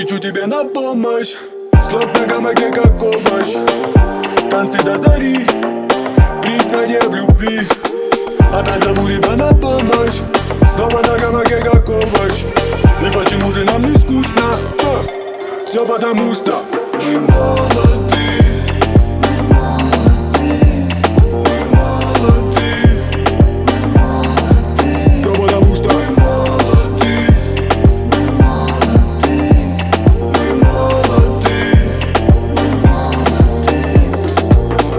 Жанр: Латиноамериканская музыка / Русские
# Urbano latino